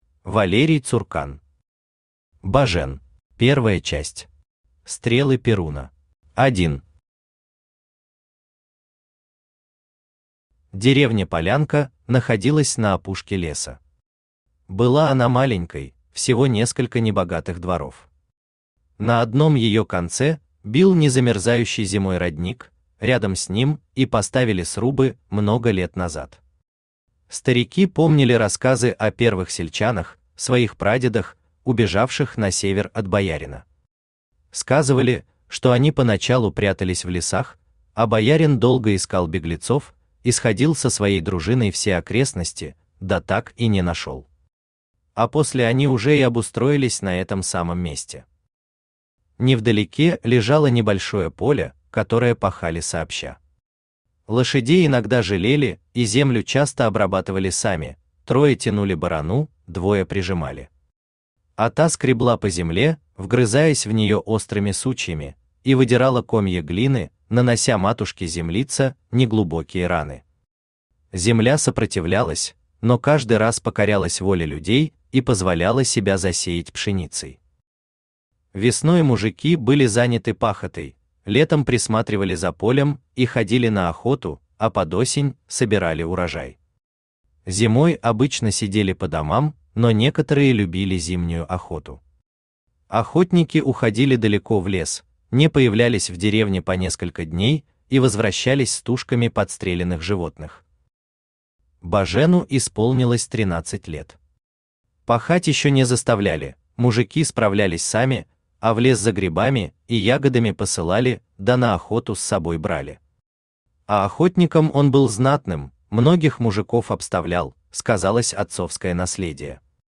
Аудиокнига Бажен | Библиотека аудиокниг
Aудиокнига Бажен Автор Валерий Цуркан Читает аудиокнигу Авточтец ЛитРес.